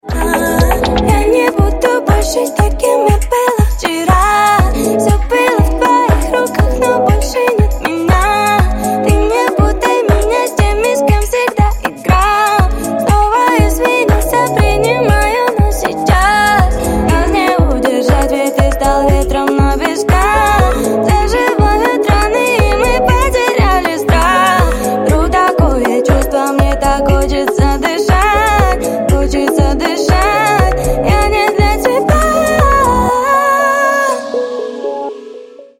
# Поп Рингтоны